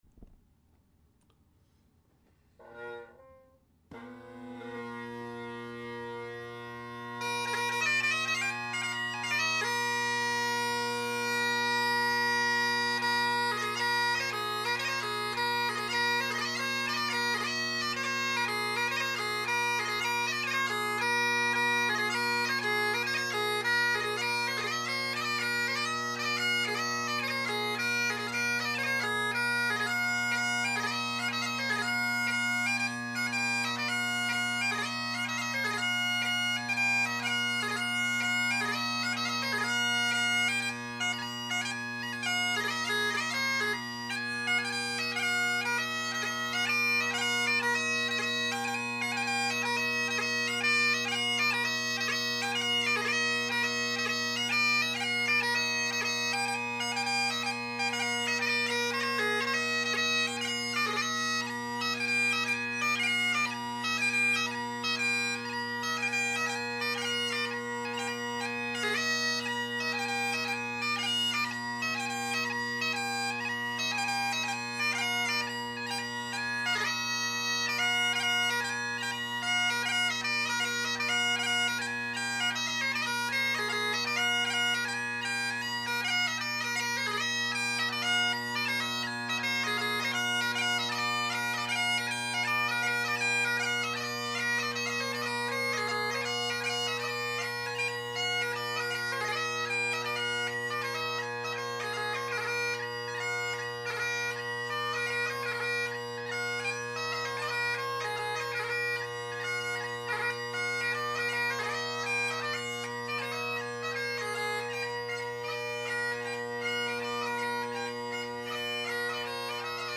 Great Highland Bagpipe Solo
1950’s Hendersons – Selbie drone reeds – Colin Kyo delrin chanter – heavily carved Apps G3 chanter reed
Be patient, each recording is unmodified from the recorder at 160 KB/s using mp3, there are a couple seconds before I strike in after I press record.